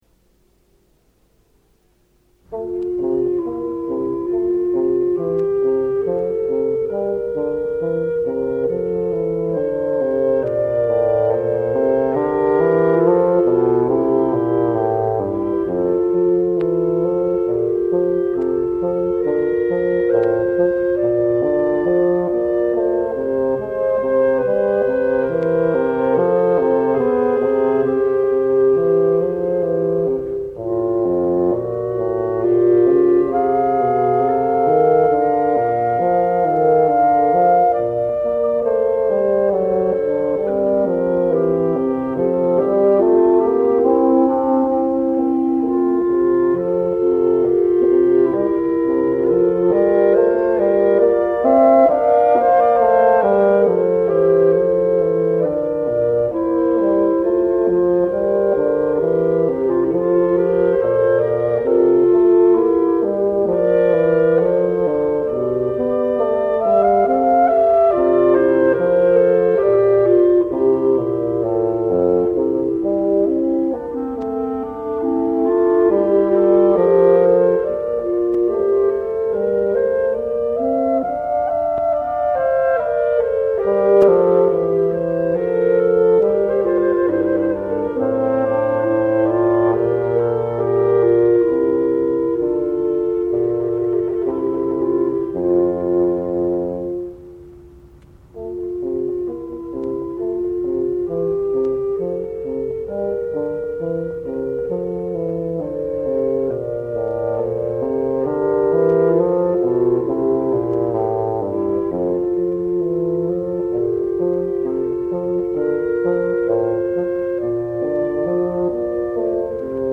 Adagio per due corni di bassetto e fagotto, in fa maggiore - K. 410 -